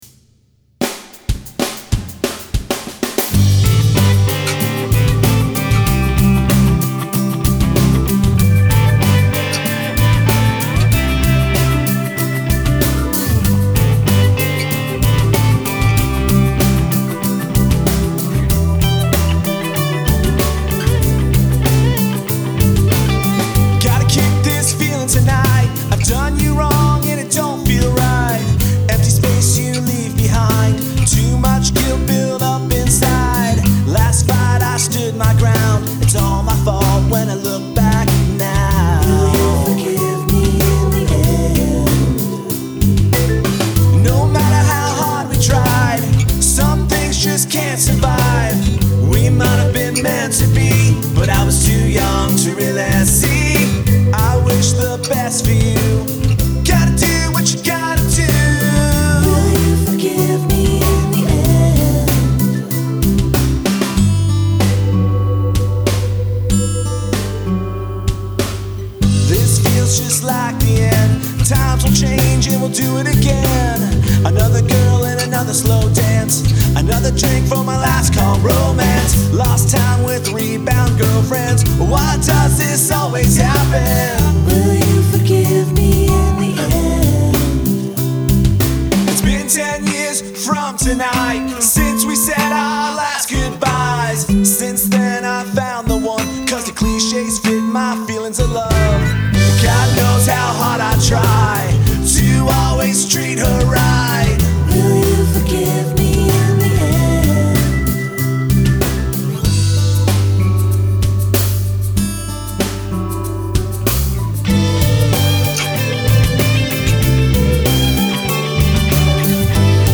All instruments, vocals
* This is a collection of home demos/ first draft versions of songs written for fun. They are here in their most raw form, captured as ideas.